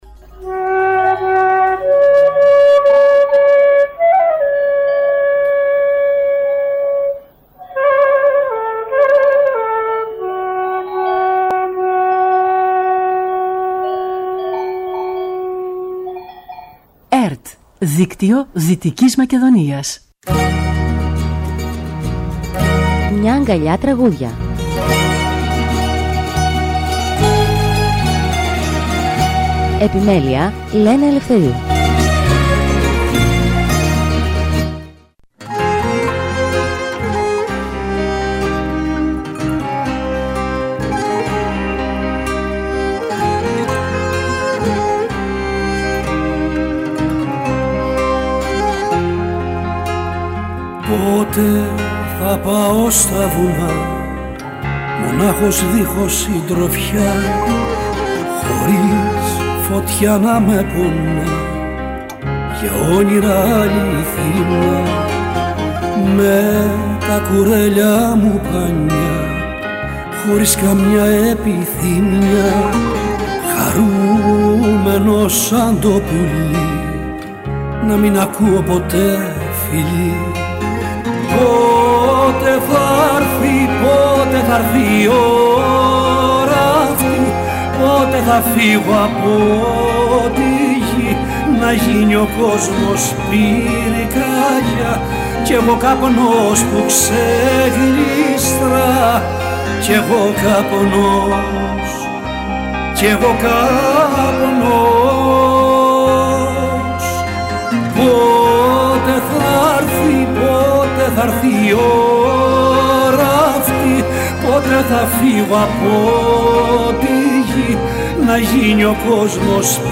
Μουσική εκπομπή που παρουσιάζει νέες δισκογραφικές δουλειές, βιβλιοπαρουσιάσεις και καλλιτεχνικές εκδηλώσεις.
ΣΥΝΕΝΤΕΥΞΗ